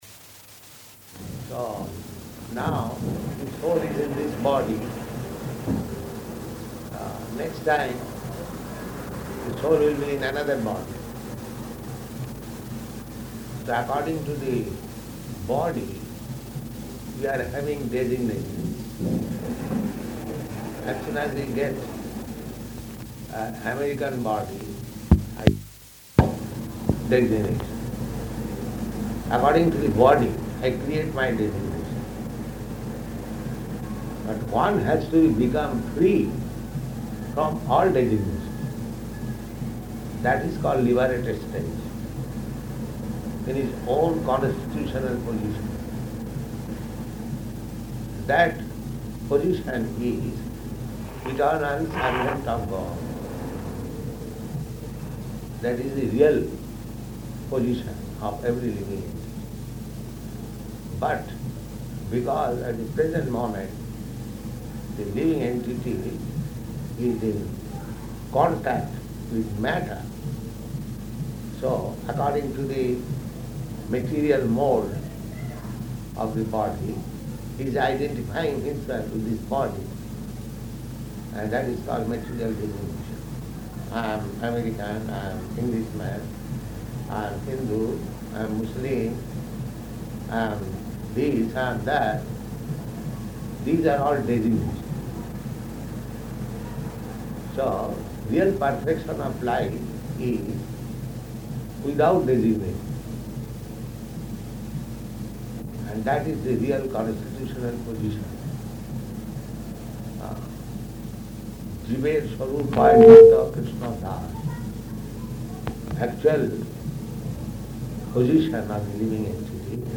Room Conversation
Type: Conversation
Location: London